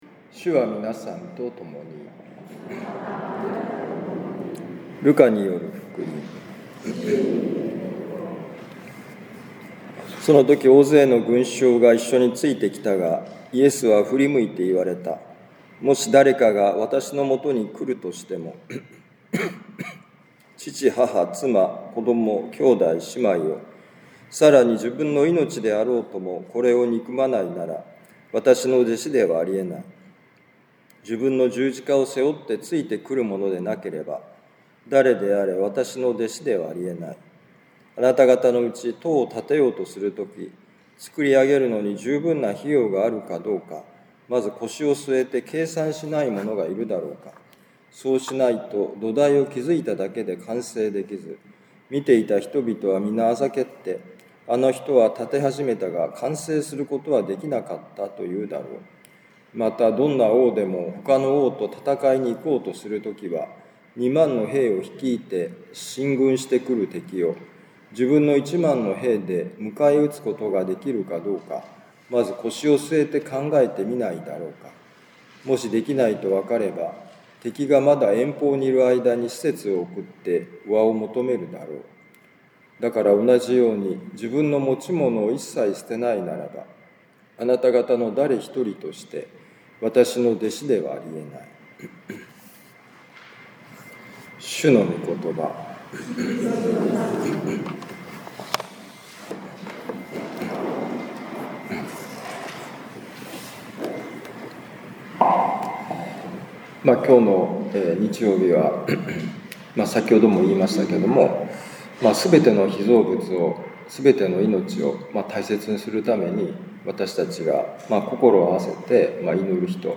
ルカ福音書14章25-33節「今こそ腰を据えて考えるとき」2025年9月7日年間第23主日ミサカトリック長府教会